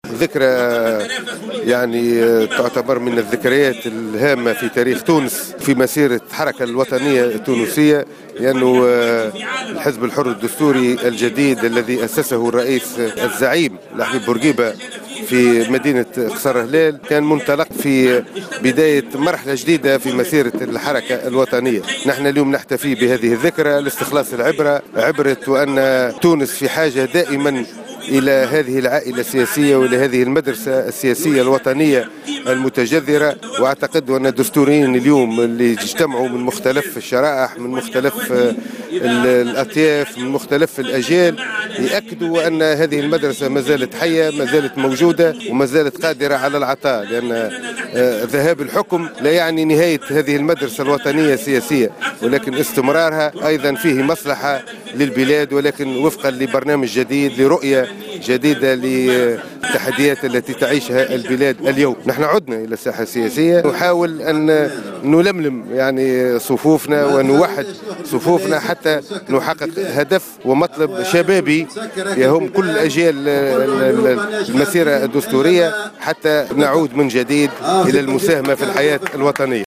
في تصريح